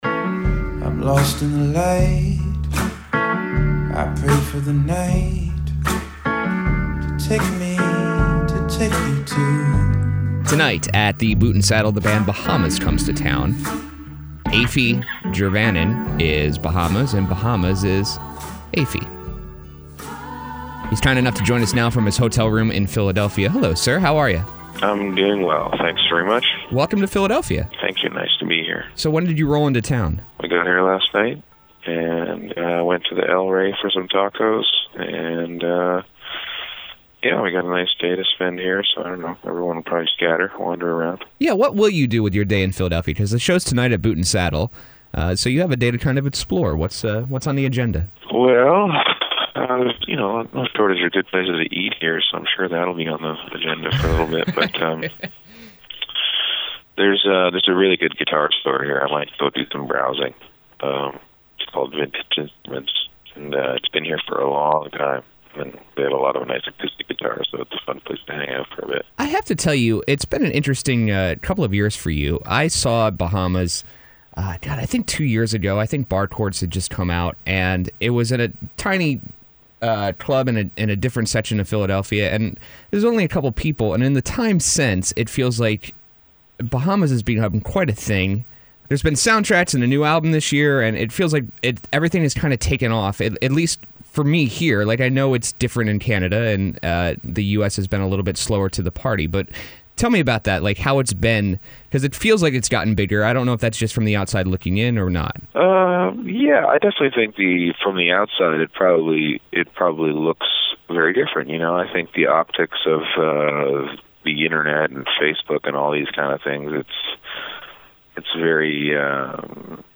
Interview: Bahamas
Tonight Bahamas comes to Philadelphia on the heels of last month’s release of “Bahamas Is Afie“. This morning he spoke with us from his hotel room about the new album and the rise of the band over the past few years.
bahamas-interview.mp3